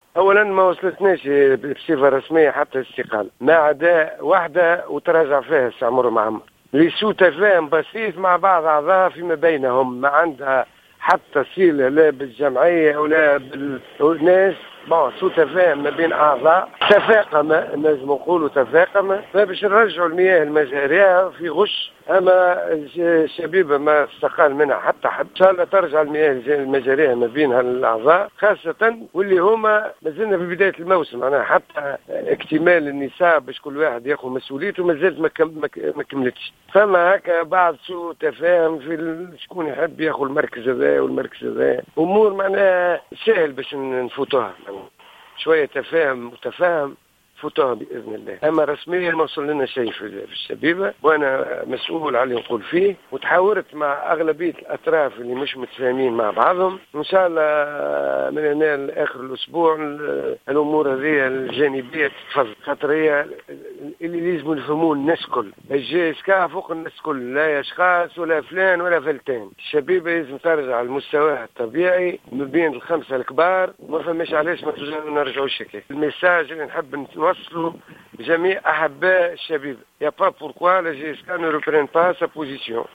في تصريح خص به جوهرة أف أم